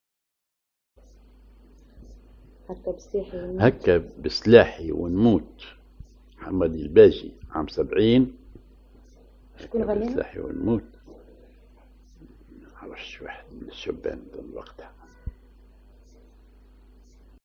ar بياتي
أغنية